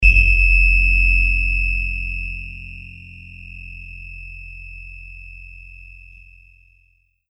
Oscillating-high